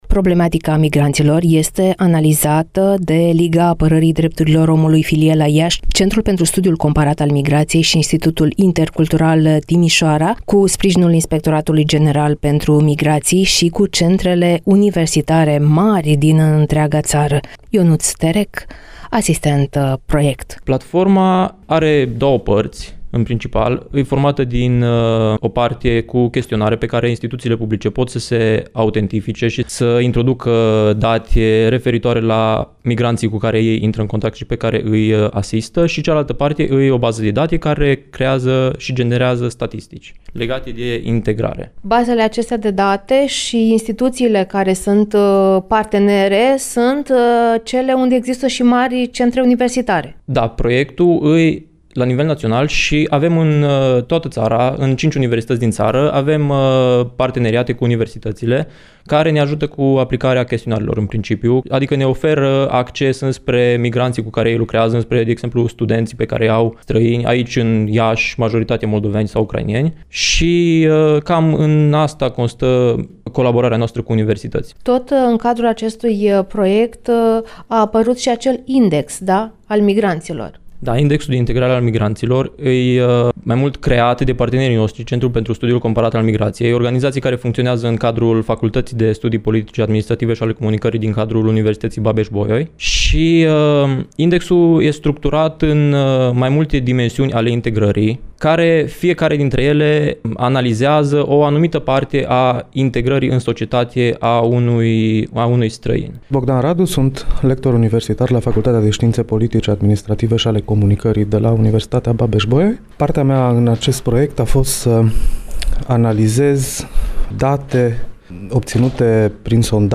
(REPORTAJ) Problematica migranților a fost dezbătută la Iași